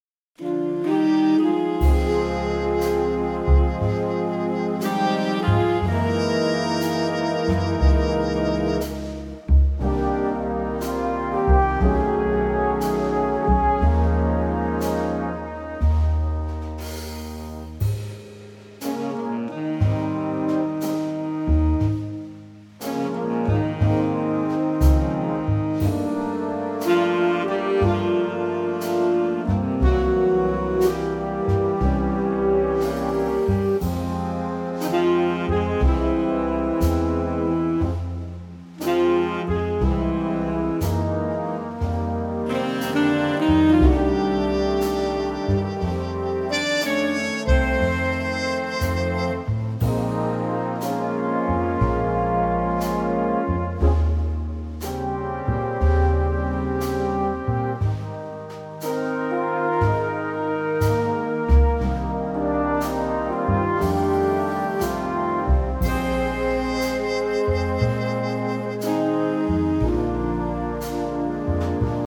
Unique Backing Tracks
key Ab
key - Ab - vocal range - Bb to Db
Stunning mellow Big Band arrangement